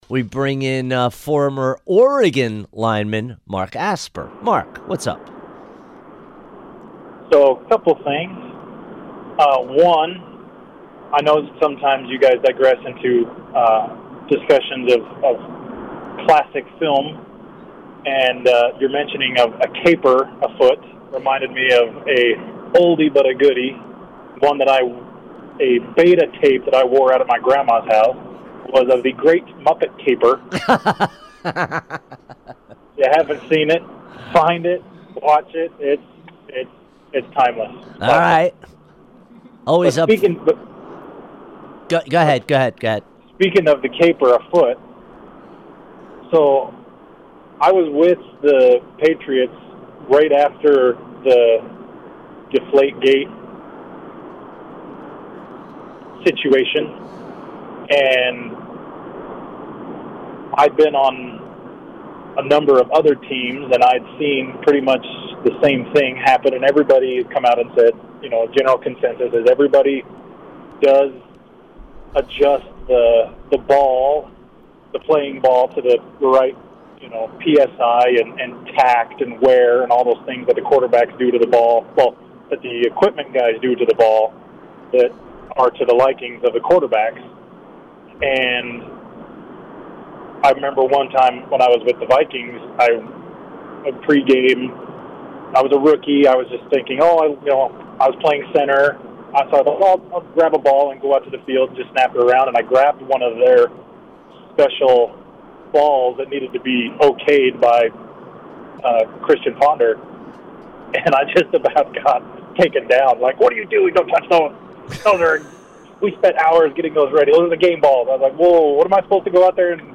calls into the show's hotline to talk about being with the Patriots post deflate-gate, and how it relates to the scandal hitting College Basketball.